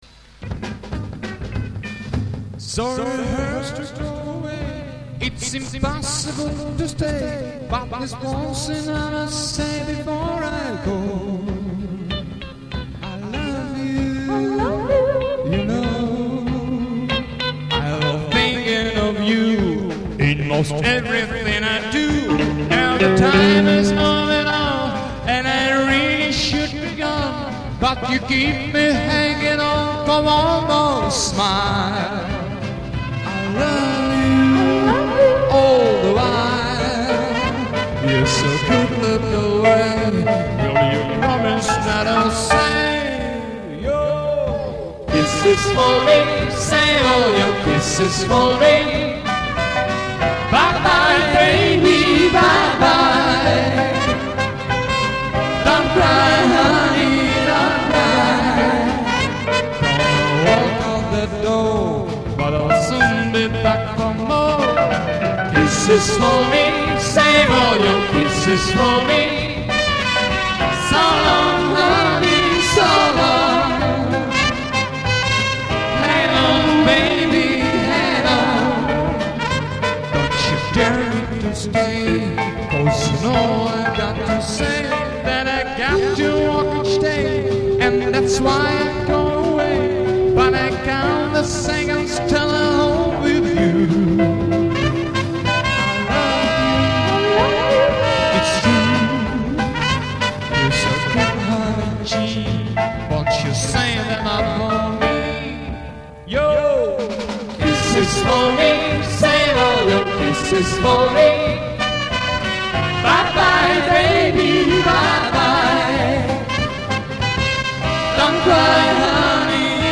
благодаря своему мощному баритону